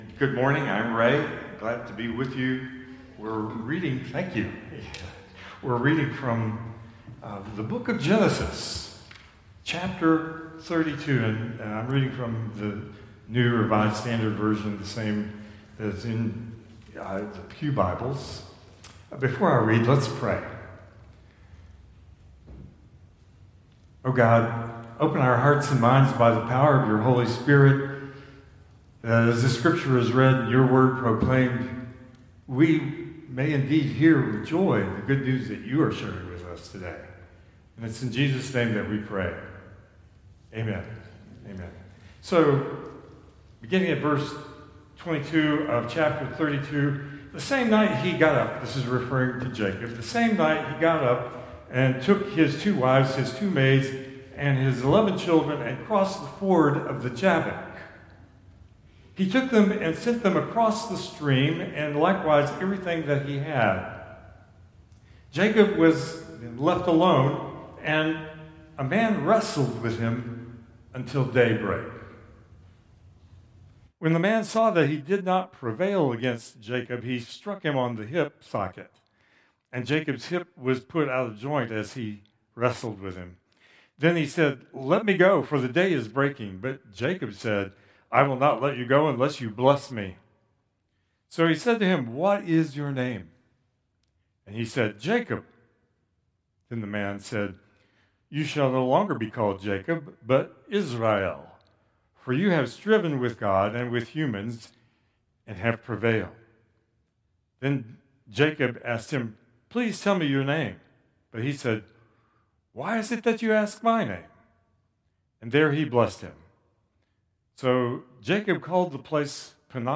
Traditional Service 9/14/2025